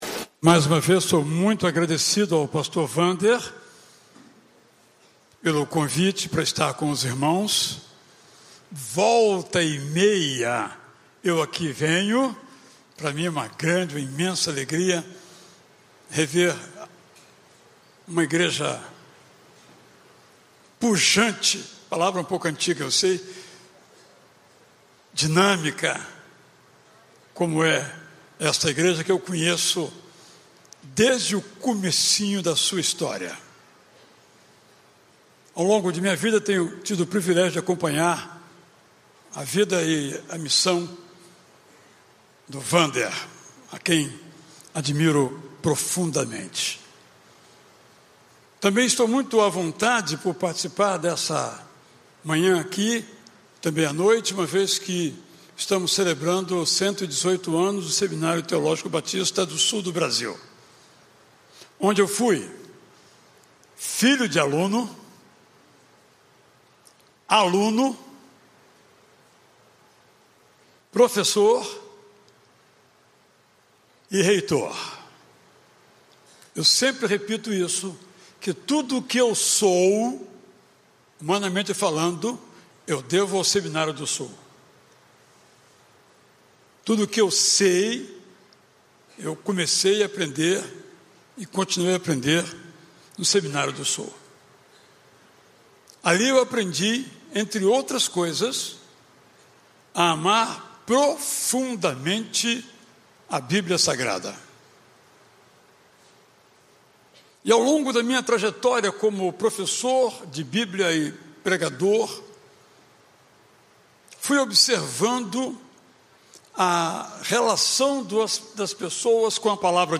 Conferência Teológica #5